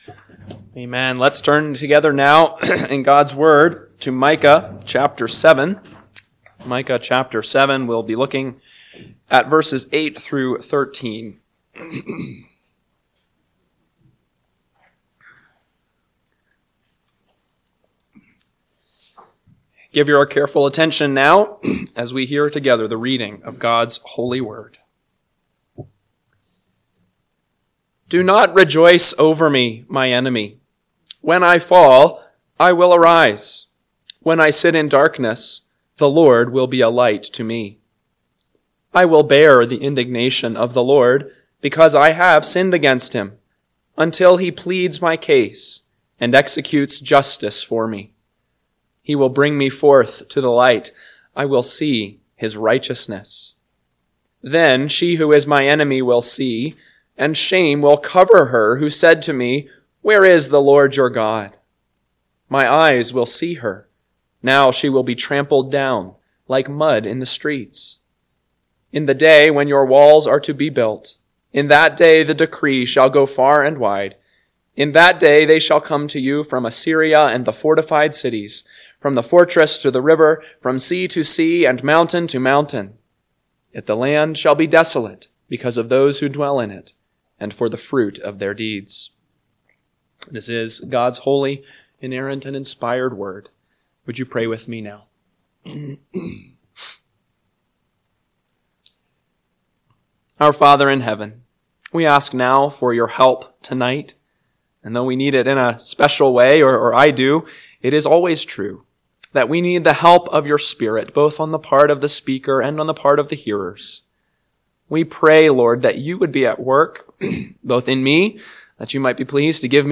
PM Sermon – 12/15/2024 – Micah 7:8-13 – Northwoods Sermons